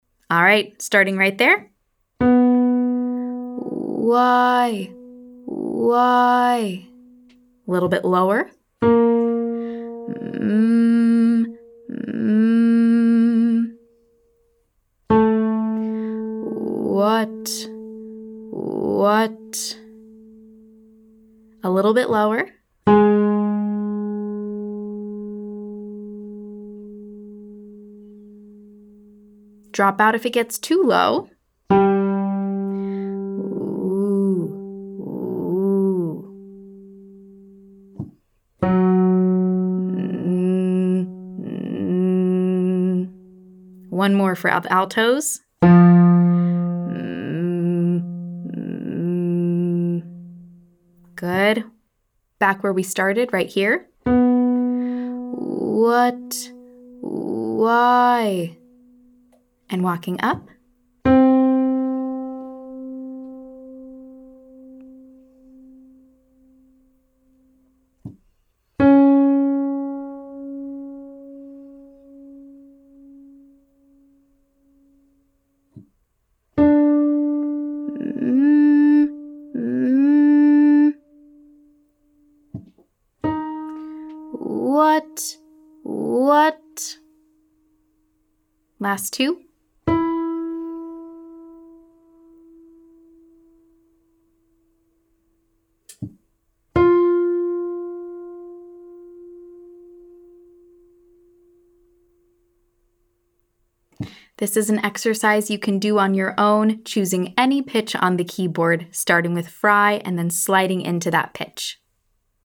I’ll play a pitch on the keyboard, and your job is to start with fry, then make your way to that pitch on a hum or a word like “why".
Exercise 2: Fry onset - fry into tone on hum or WHY (slide into specific pitch)